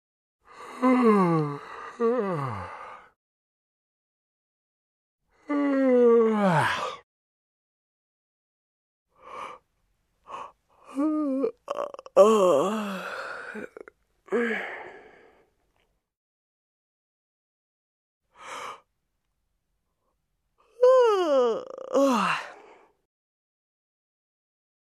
Биение сердца — звуковой эффект Скачать звук music_note Анатомия , тело человека save_as 1 Мб schedule 1:06:00 30 0 Теги: mp3 , анатомические звуки , анатомия , звук , сердце , человек , человеческие звуки